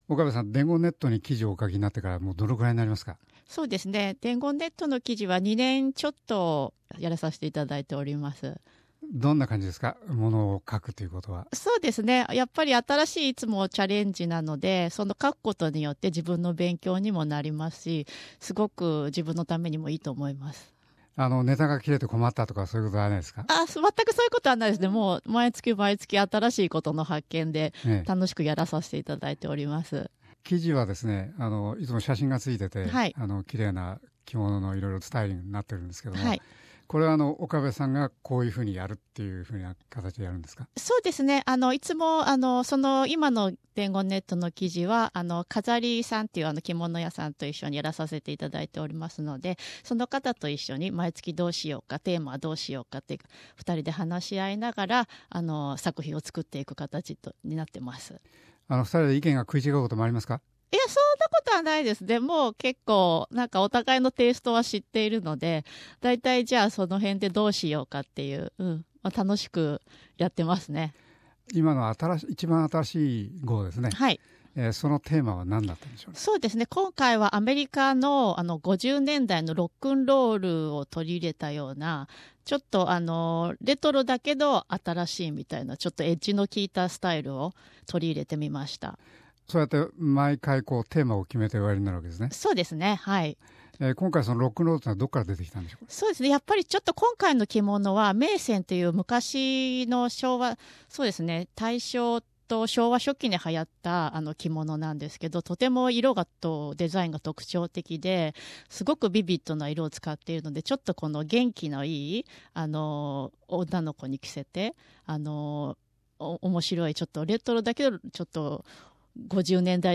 in a SBS Studio